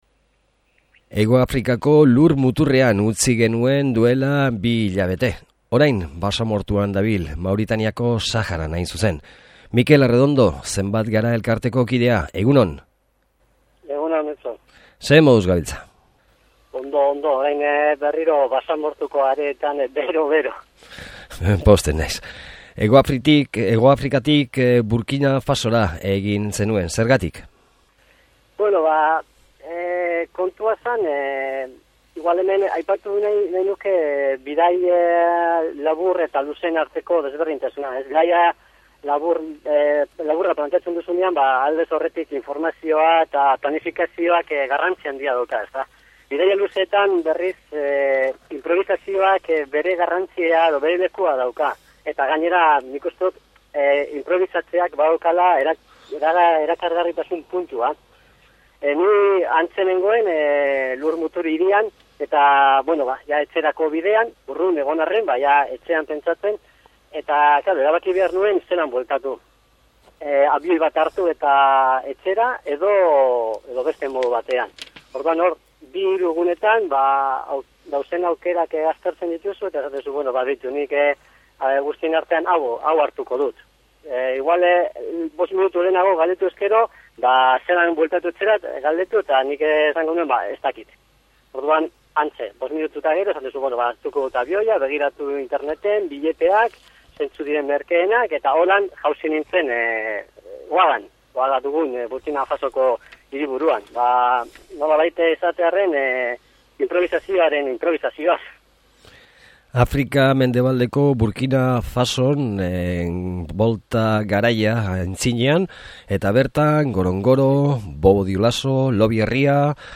SOLASALDIA: Afrika Mendebaldean